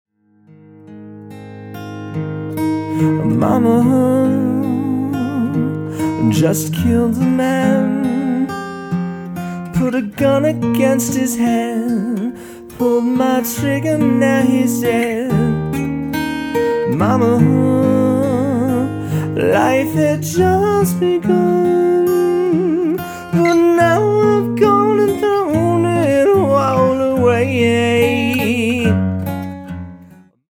” pro-quality, all live in my home studio